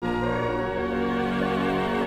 At the beginning of the extraction, there still is some residuum from the strings audible and visible.
Note that in the residual (signal – extraction) the voice is mainly audible in the beginning when it is not yet modulating in frequency. As soon as the vibrato begins, the contribution of the voice in the extraction begins to become significant (and drastically drops in the residual).